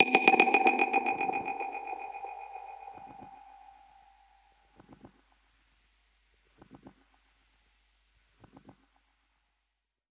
Index of /musicradar/dub-percussion-samples/95bpm
DPFX_PercHit_A_95-03.wav